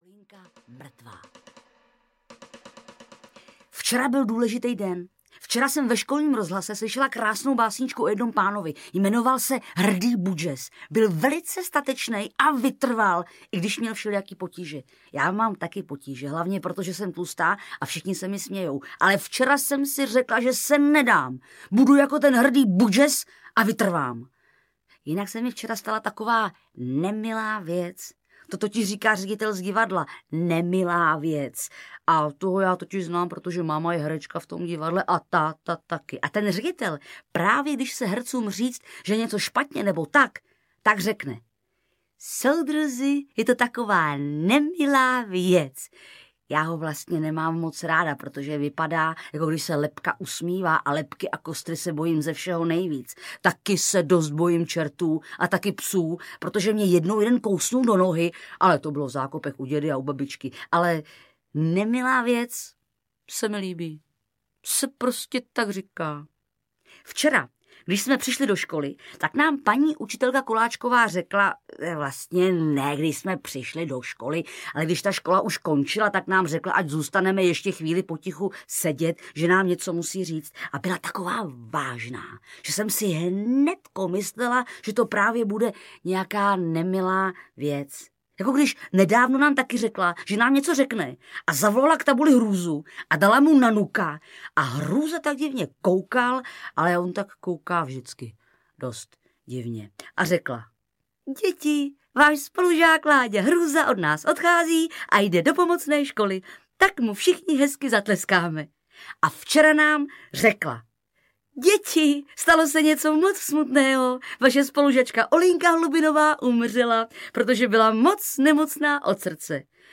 Audio kniha
Ukázka z knihy
Naše zvuková verze vychází z knižní předlohy, ale zároveň využívá osvědčených postupů divadelního zpracování.
Zvuková verze shromáždila oba knižní bestselery v jednom mimořádném kompletu, s ideální představitelkou, Barborou Hrzánovou.